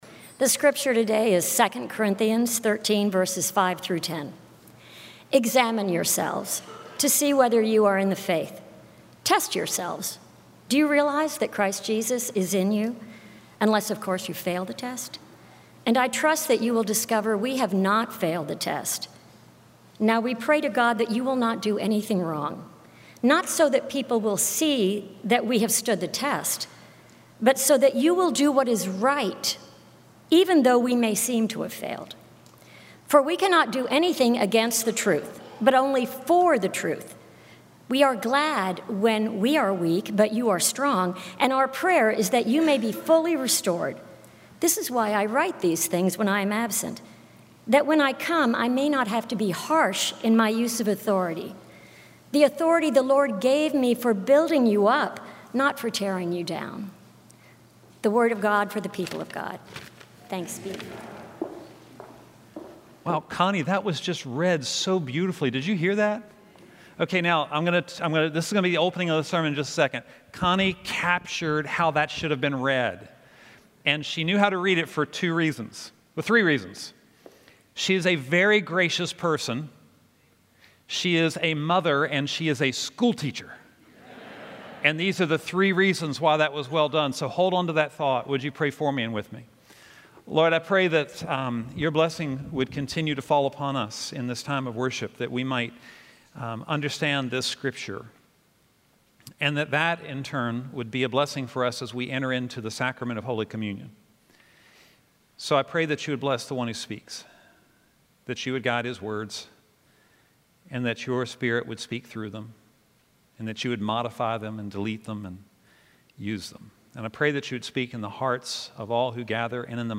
Scripture: 2 Corinthians 13:5-10, Fifth Sunday of Lent and Communion Full restoration requires us to participate in our own success. God expects us to contribute some sweat equity to our transformation process.